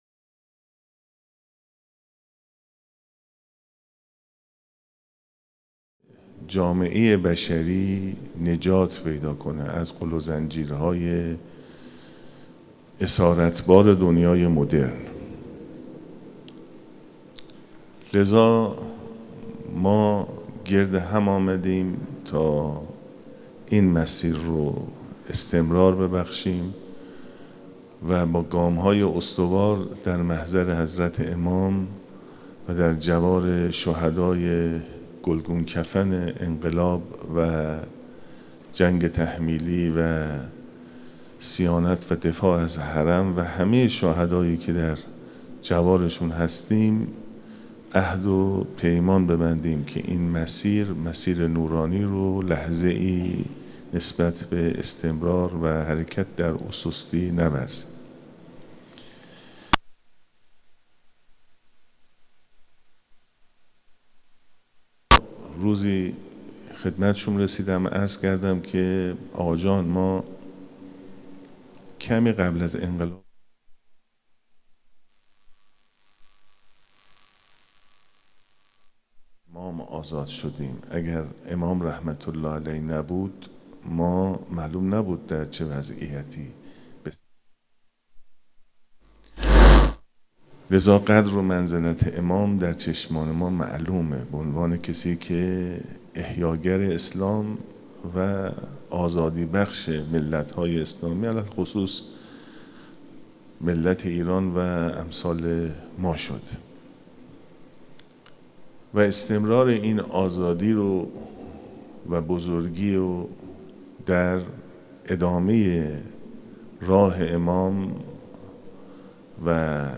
سخنرانی دکتر علیرضا زاکانی در هشتمین گردهمایی ملی هیأت‌های فعالان مردمی در عرصه اربعین با موضوعیت اربعین و بلد طیب | تهران - حرم امام خمینی (ره) | 19 و 20 مردادماه 1402 | جامعه ایمانی مشعر